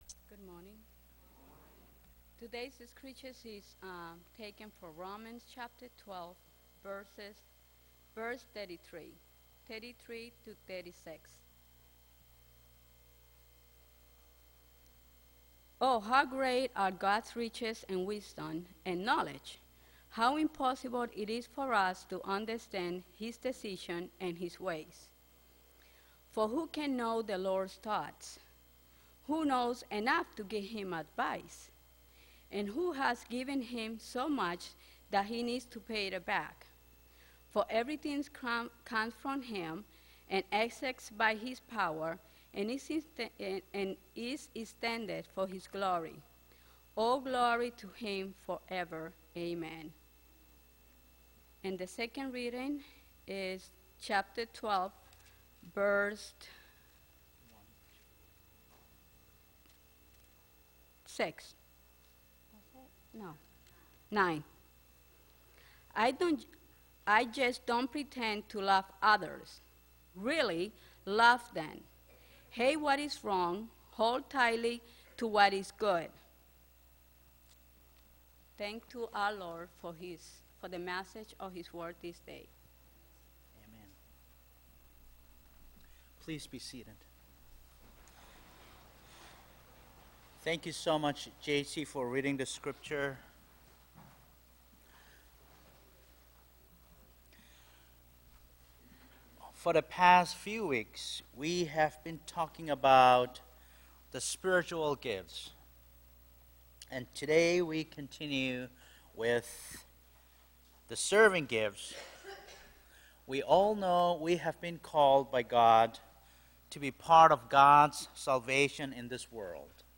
Audio Recording of Sept 20th Worship Service – Now Available
The audio recording of our latest Worship Service is now available.